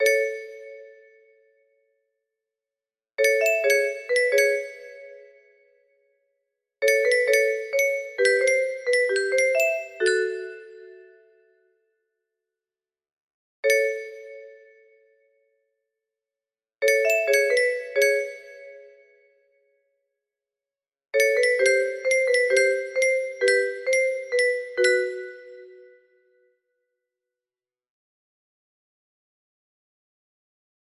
17-24 music box melody